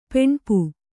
♪ peṇpu